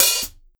Cardi Open Hat 3.wav